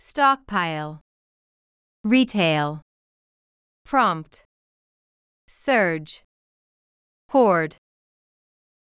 stockpile /ˈstɑːkˌpaɪl/（名）備蓄、貯蔵
retail /ˈriːˌteɪl/（名）小売（動）小売する
prompt /prɑːmpt/（動）促す、引き起こす
surge /sɝːdʒ/（名）急上昇、急増
hoard /hɔːrd/（動）買い占める、貯め込む